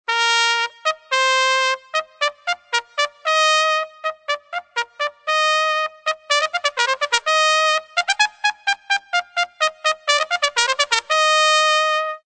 TRUMSOLO.mp3